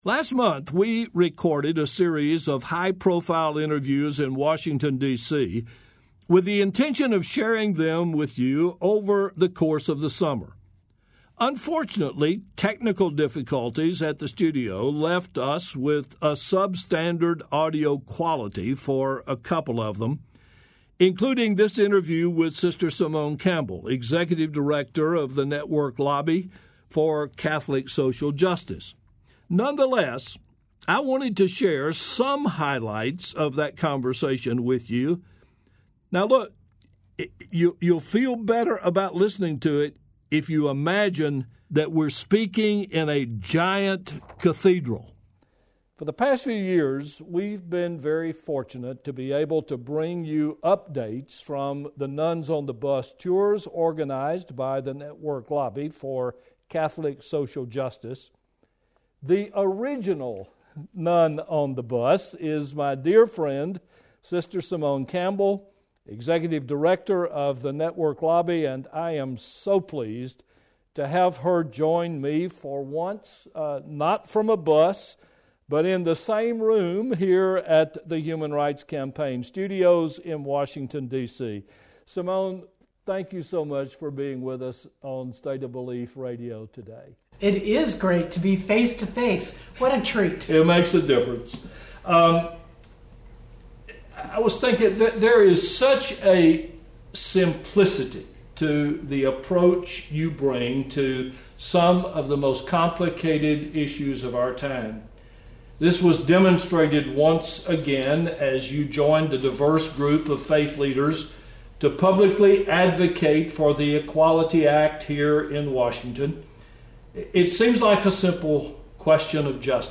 This week, you’ll hear highlights from Welton’s conversation with Sister Simone as they discuss the current state of affairs in D.C. and what is on the horizon in the push for social justice.
This conversation is part of Whosoever You Love, our semi-monthly series affirming the worth and full value of LGBTQ persons within and beyond religion.